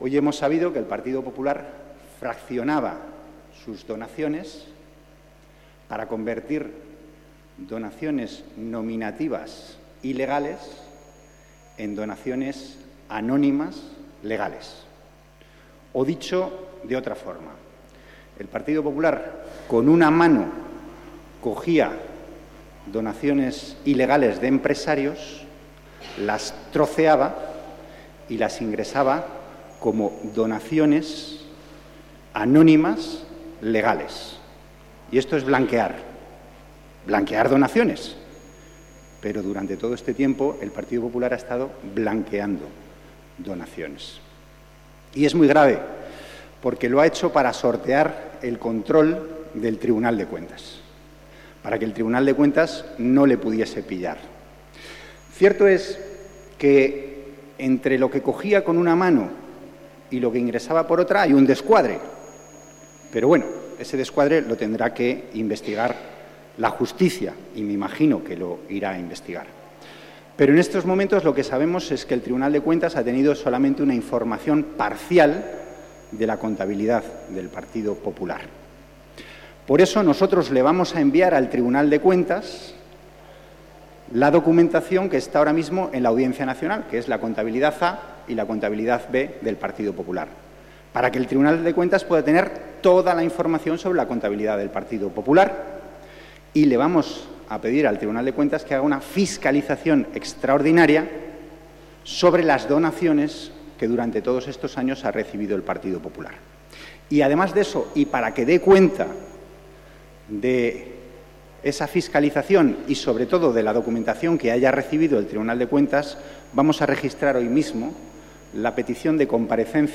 Declaraciones de Antonio Hernando en el Congreso tras la publicación en El País sobre los manejos contables del PP y de Bárcenas 17/04/2013